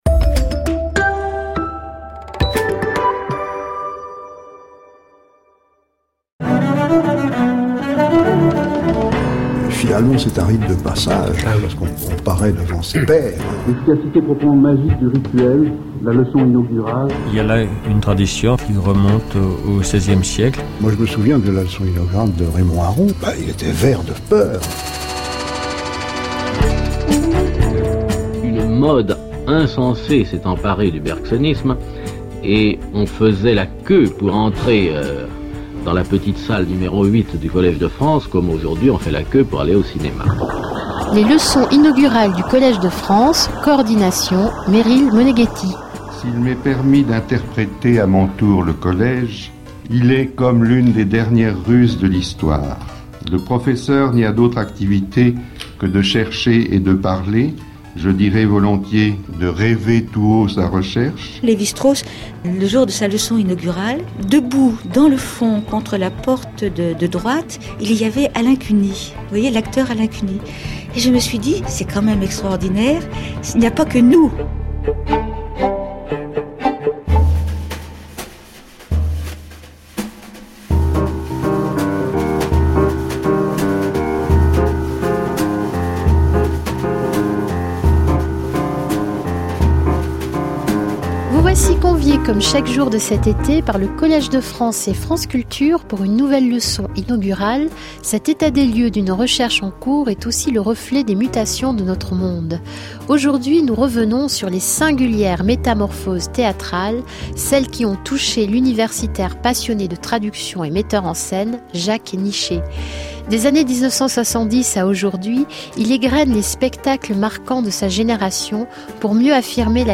Ecoutez la leçon inaugurale de Jacques Nichet au Collège de France en 2010 (chaire de création artistique)